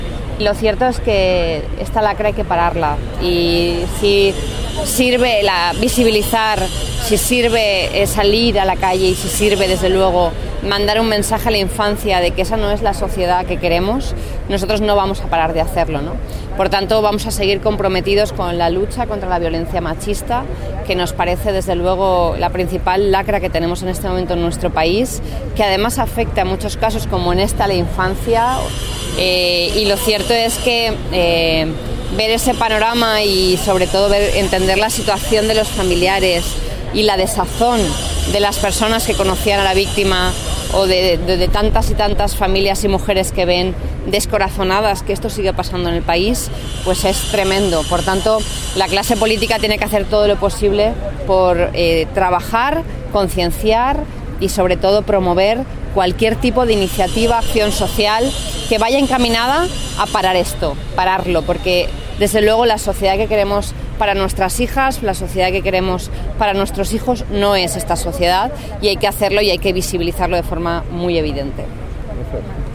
• La corporación municipal, durante la concentración silenciosa
La corporación municipal se ha concentrado este viernes en la plaza del Ayuntamiento, ante las puertas del edificio consistorial, en rechazo por el último caso de violencia de género, perpetrado el miércoles en la localidad valenciana de Sagunto.